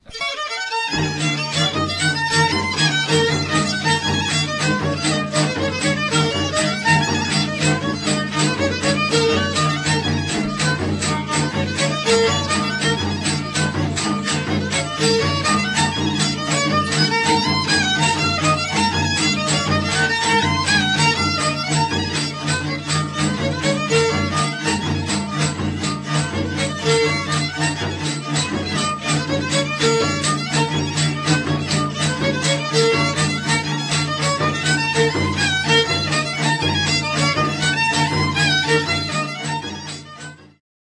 7. Mazurek (1993 r.)
W tle słychać było głosy, jęki, nawoływania.
skrzypce
basy 3-strunowe
bębenek